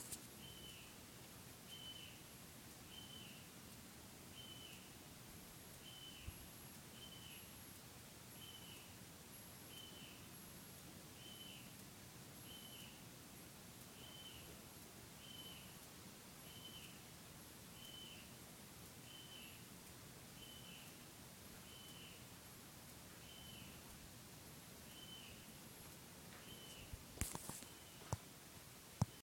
Tschudi´s Nightjar (Quechuavis decussata)
Detailed location: Magdalena del Mar
Condition: Wild
Certainty: Recorded vocal